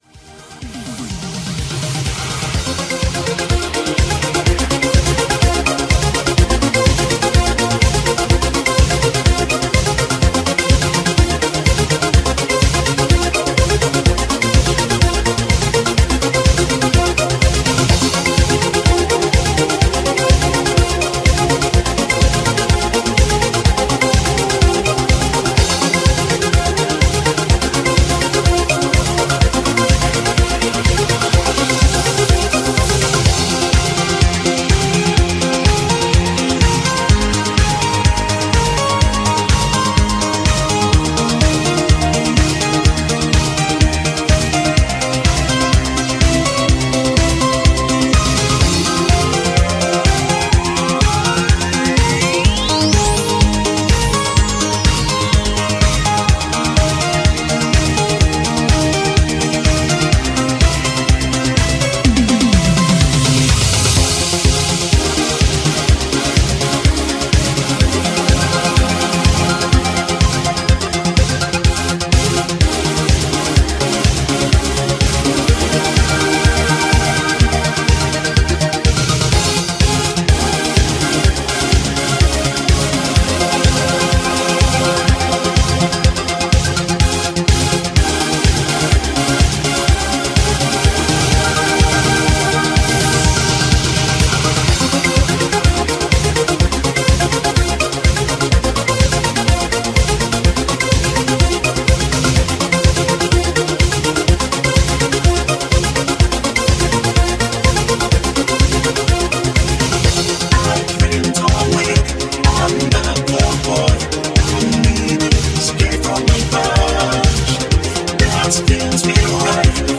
_ ITALO DISCO MEGAMIX _
_-ITALO-DISCO-MEGAMIX-_.mp3